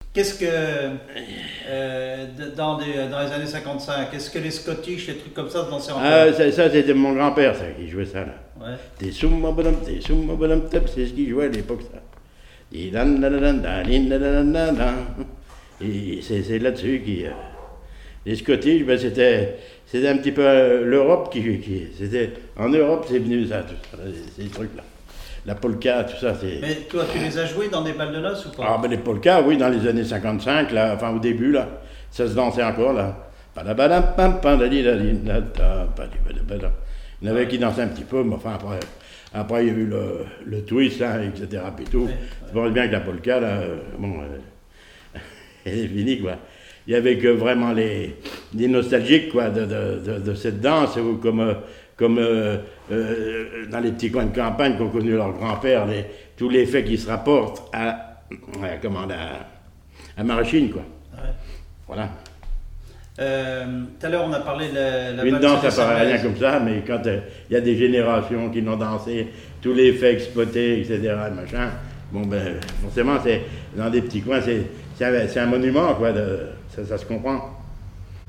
clarinette, clarinettiste ; musique traditionnelle ; musicien(s) ;
Témoignage sur la musique
Catégorie Témoignage